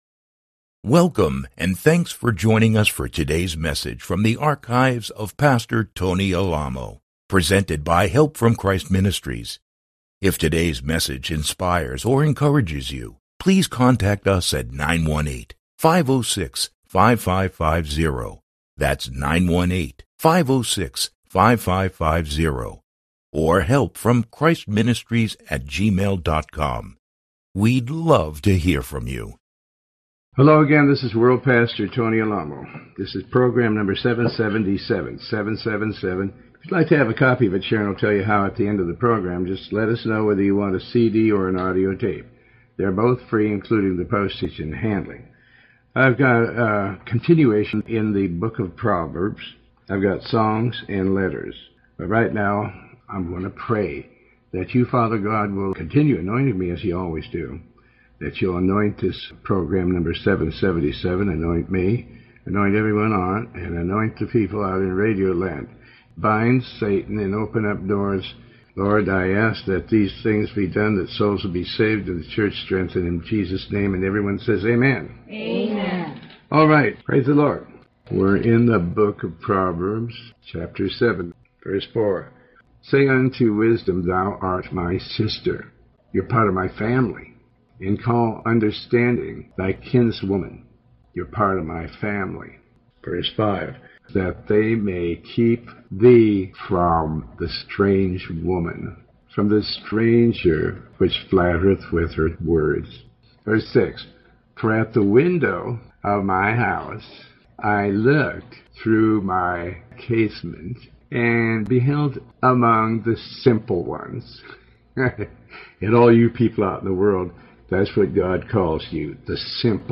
Talk Show Episode, Audio Podcast, Tony Alamo and Paster Tony Alamo Speaks Show 777B on , show guests , about Tony Alamo Christian Ministries,pastor tony alamo,Faith, categorized as Health & Lifestyle,History,Love & Relationships,Philosophy,Psychology,Christianity,Inspirational,Motivational,Society and Culture